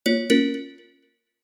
sysnotify.ogg